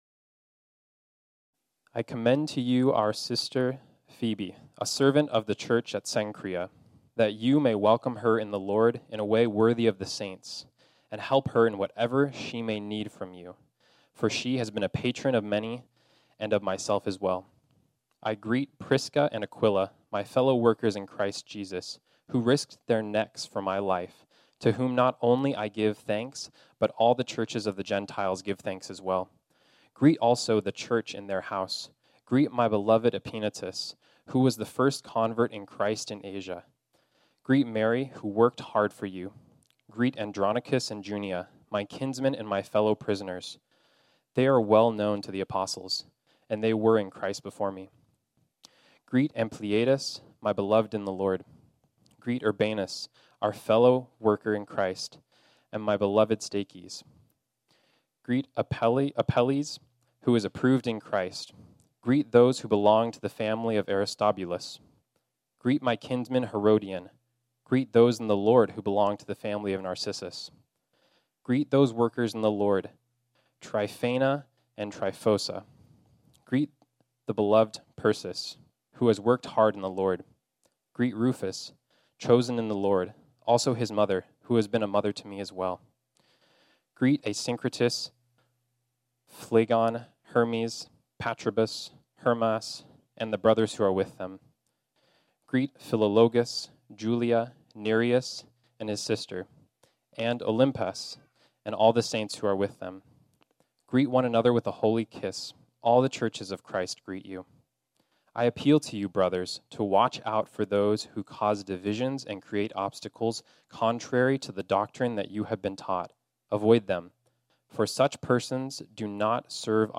This sermon was originally preached on Sunday, September 5, 2021.